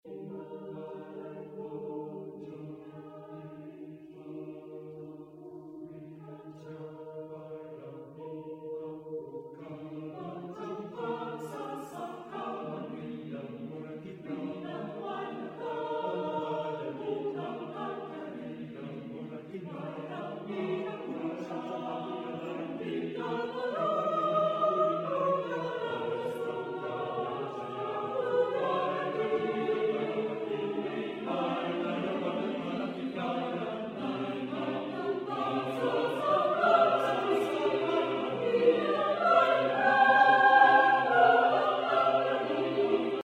• Voicing: ssaattbb
• Accompaniment: a cappella